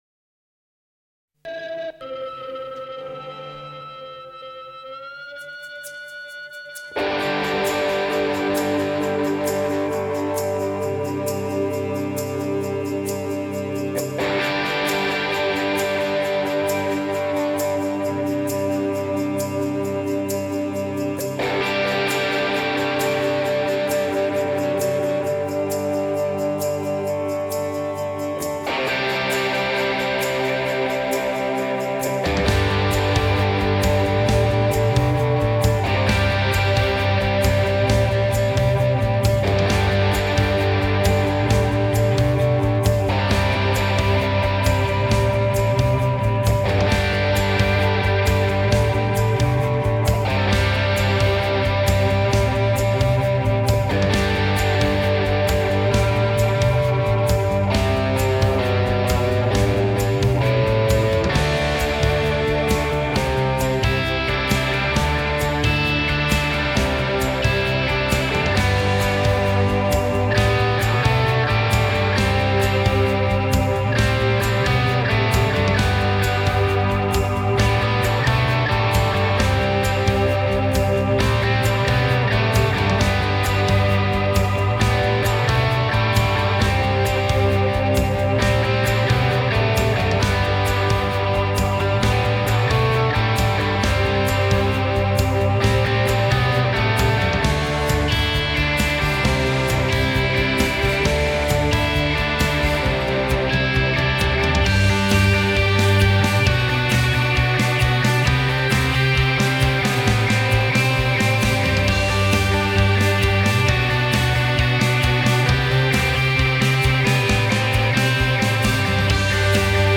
BPM : 132
Tuning : E
Without vocals